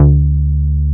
R MOOG D3MP.wav